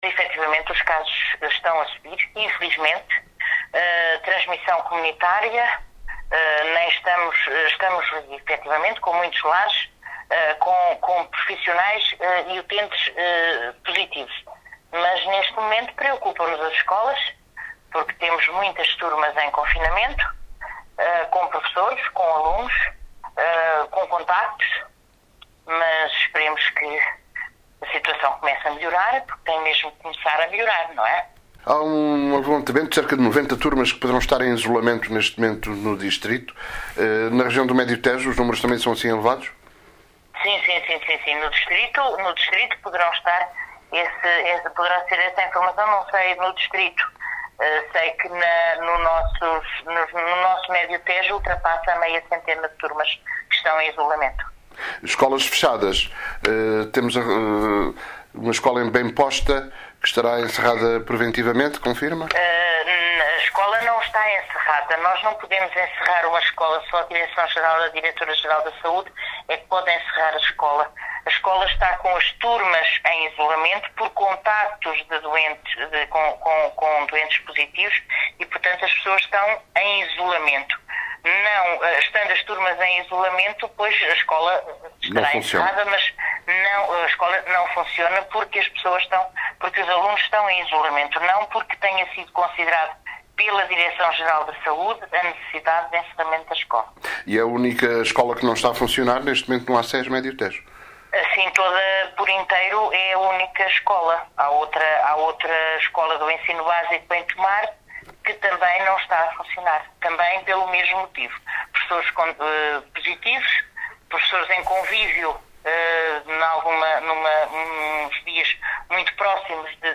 ÁUDIO DELEGADA DE SAÚDE ACES MÉDIO TEJO: